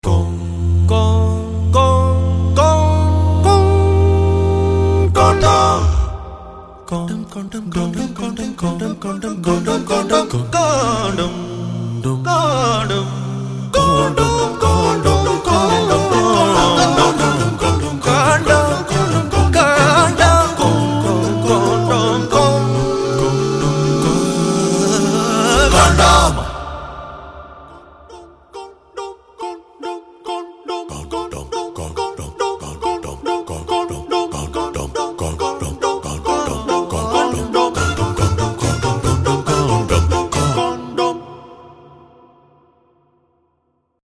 We recorded a condom-themed ringtone.
We also decided to use a ringtone because, when the phone rings, the tune - or, in our case, the word "condom" - is put out in public.
condom_acappella_ringtone.mp3